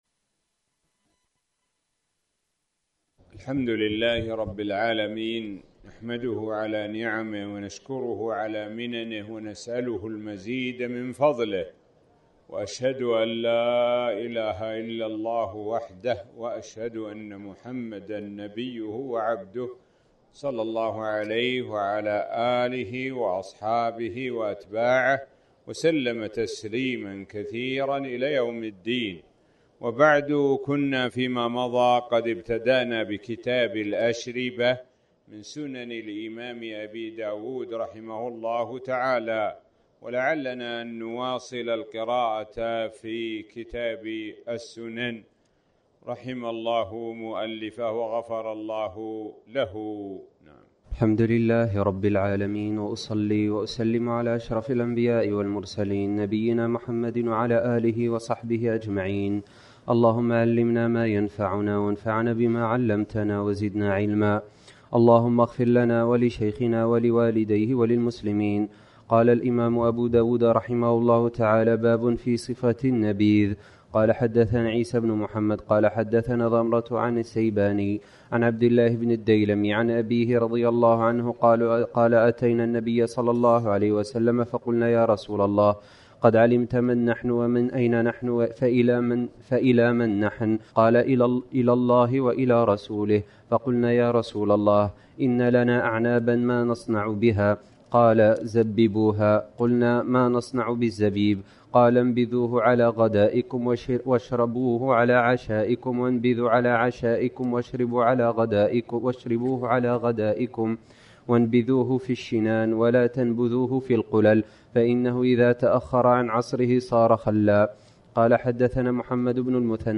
الموقع الرسمي لفضيلة الشيخ الدكتور سعد بن ناصر الشثرى | الدرس--4 [ باب في صفة النبيذ ]